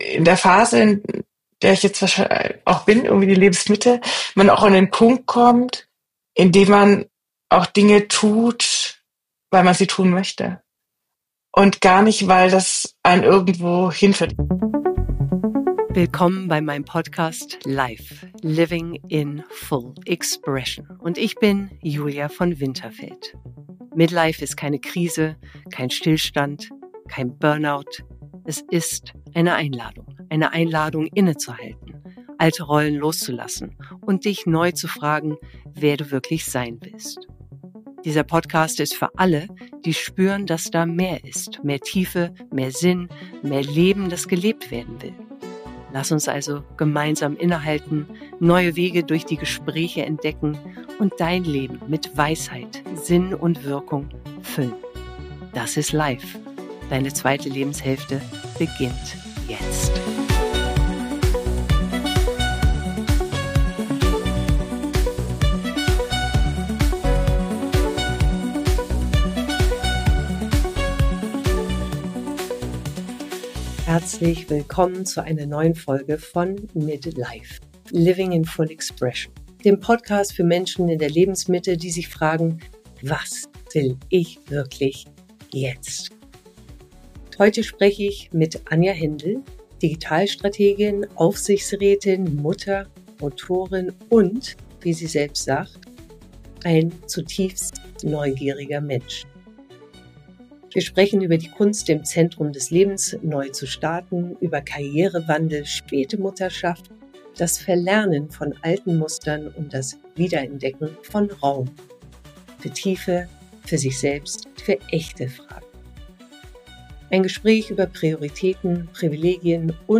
Ein Gespräch über Neugier, innere Führung, Vereinbarkeit – und darüber, wie sich Erfolg neu anfühlen darf.